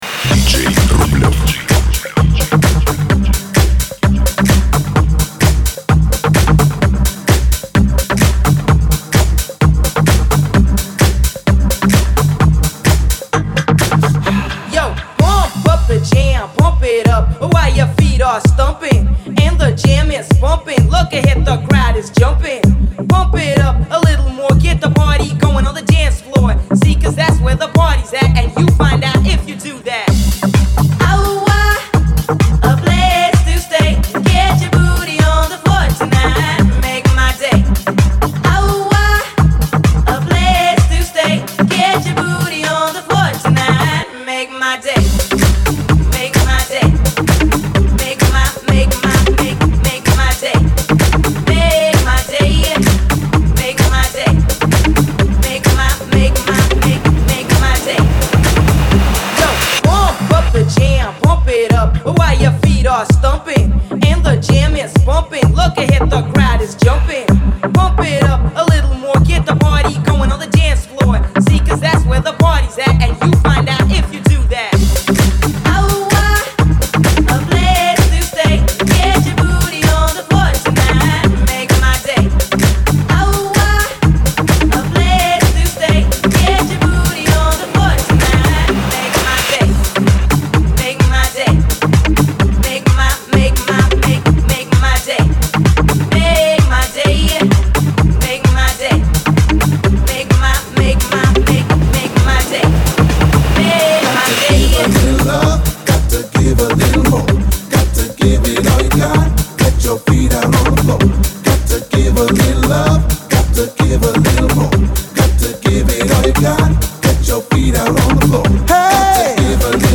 Жанр: Dance music